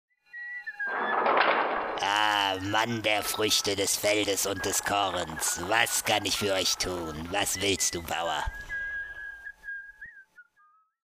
Deutsche Sprecher (m)